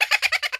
SSBM-ST_GREATBAY_TKLE_LAUGH.ogg.mp3